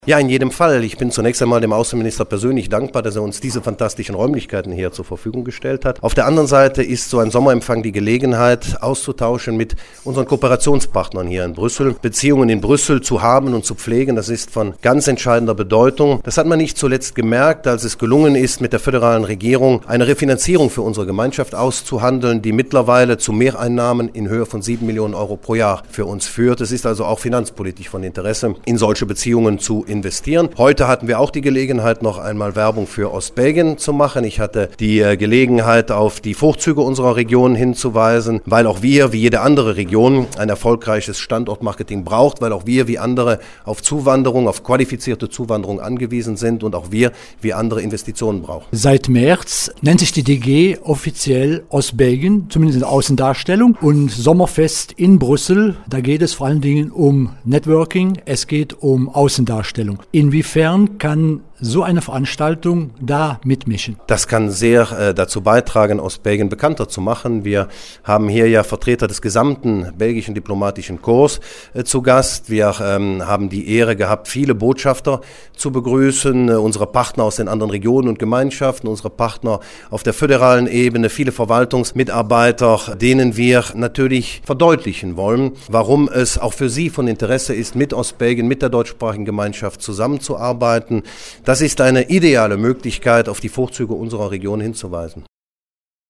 Am Dienstagabend fand im Egmontpalast in der Hauptstadt das 16.Sommerfest der DG statt.
Er fragte Ministerpräsident Oliver Paasch, ob ein solches Fest nach wie vor eine große Bedeutung habe: